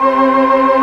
Index of /90_sSampleCDs/Giga Samples Collection/Organ/Barton Melo 16+8